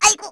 Luna-Vox_Damage_kr_01.wav